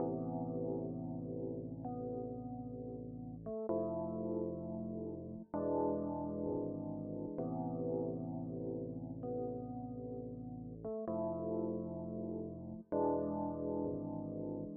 爵士电钢琴
Tag: 130 bpm Hip Hop Loops Piano Loops 2.48 MB wav Key : Unknown